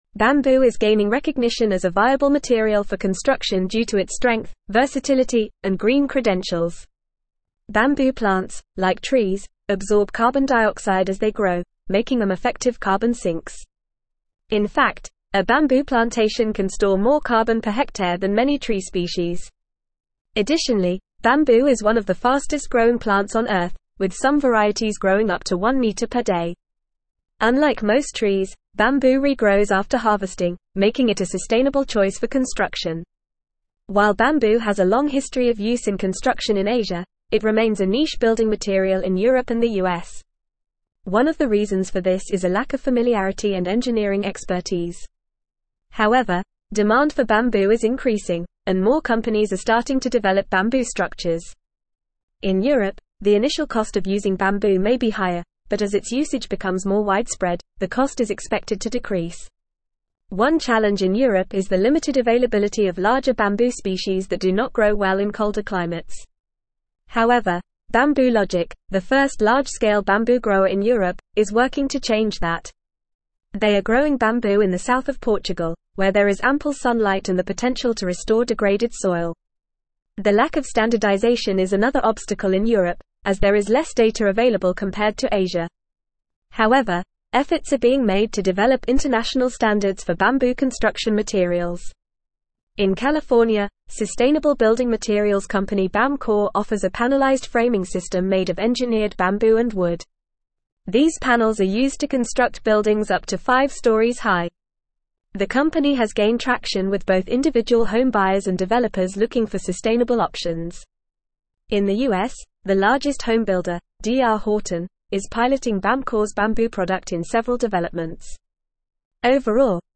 Fast
English-Newsroom-Advanced-FAST-Reading-Bamboo-A-Sustainable-and-Versatile-Building-Material.mp3